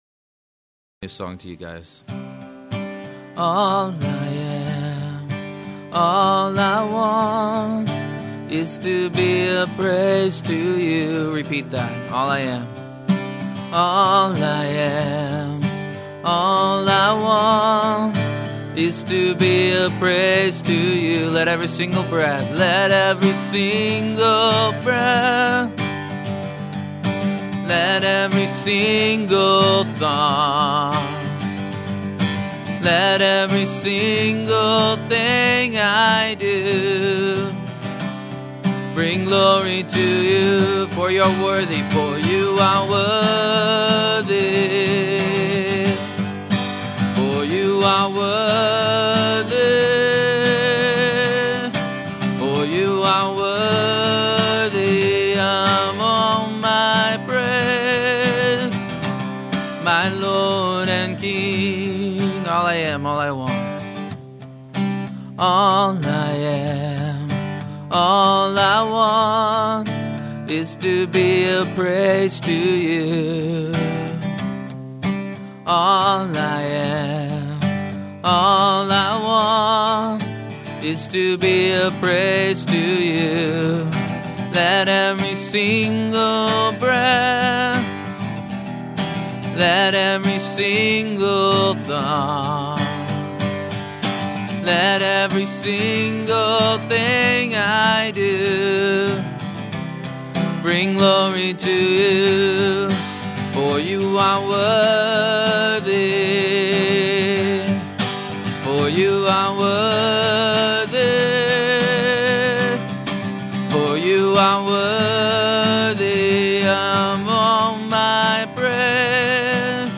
Series: 2006 Calvary Chapel Worship Leader Conference
Service Type: Workshop